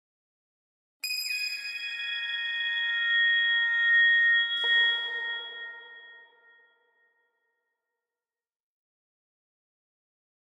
High Strings
Harmonic Pad Harmonic Plucks Harmonic Pad - Short Very High Pad With Some Pizzicatos Version 1